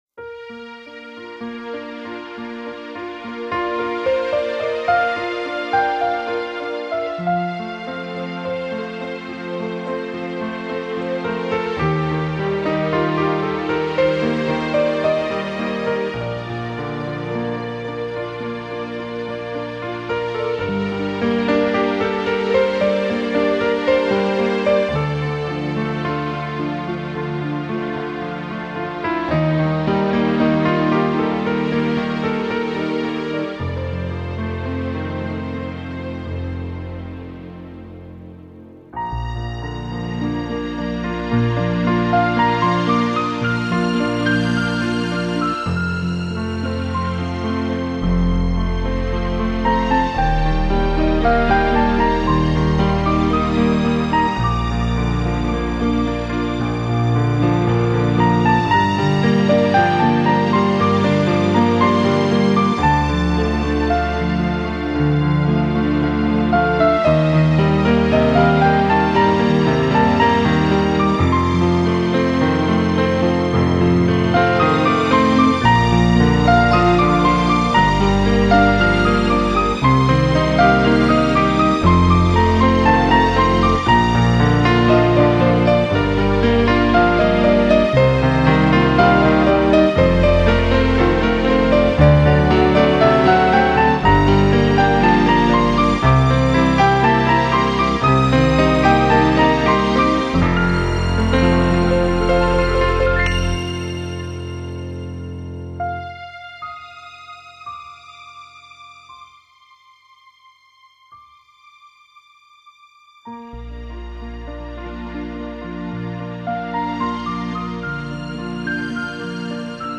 Genre:New Age, Instrumental, Neo-Classical, Piano